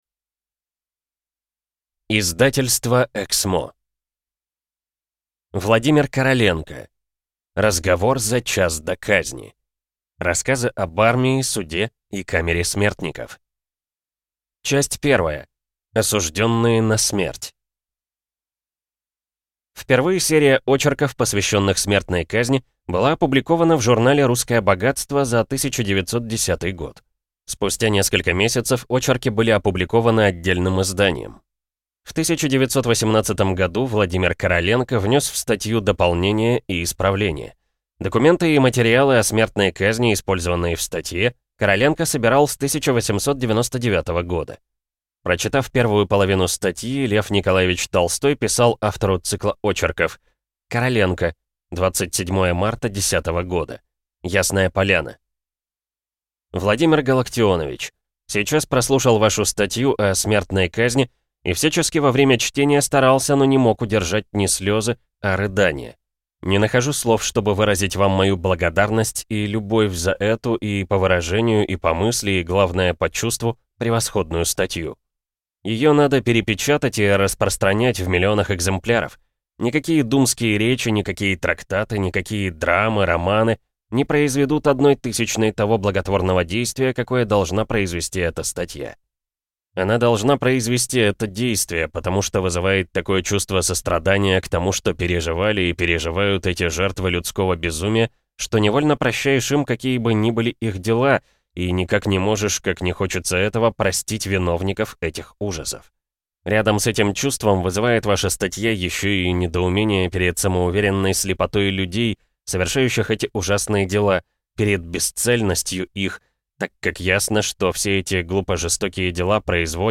Аудиокнига Разговор за час до казни. Рассказы о преступниках, суде и камере смертников | Библиотека аудиокниг